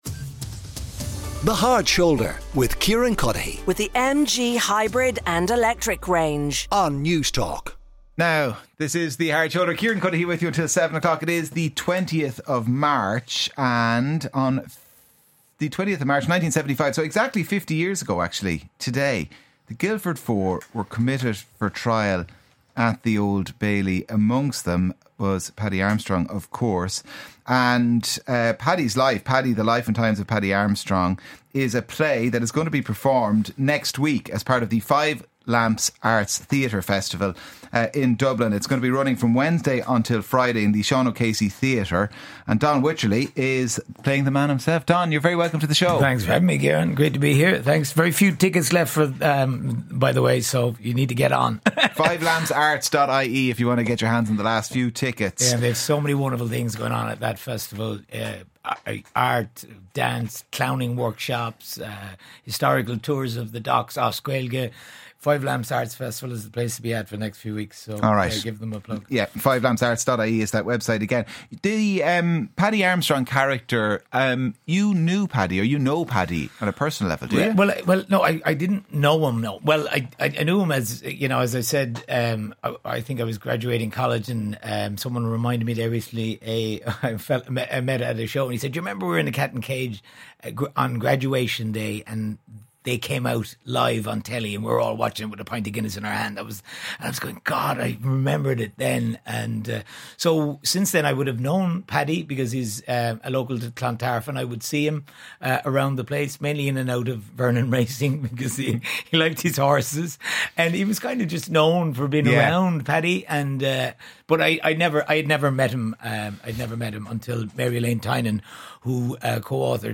Play Rate Apps Listened List Bookmark Share Get this podcast via API From The Podcast The Thursday Interview on The Hard Shoulder The Hard Shoulder sits down with public figures from the world of sport, politics, entertainment and business for wide-ranging chats about their life stories.